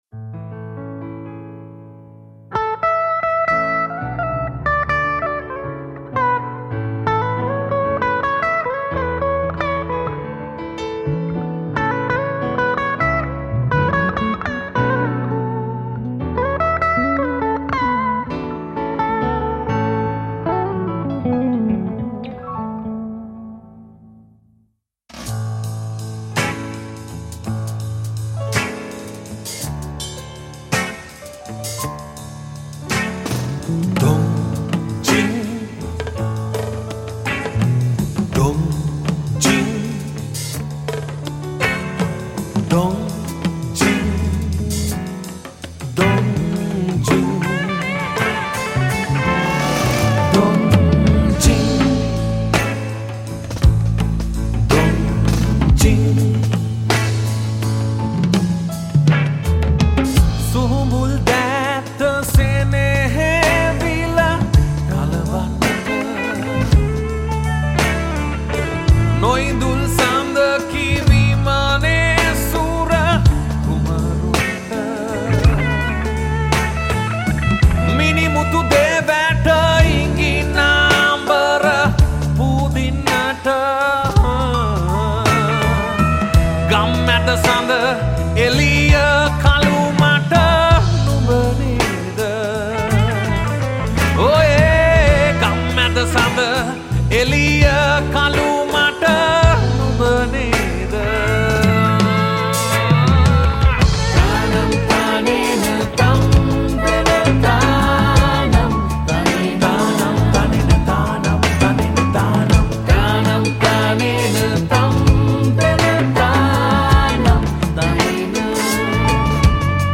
High quality Sri Lankan remix MP3 (4.4).